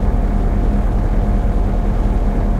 trash.ogg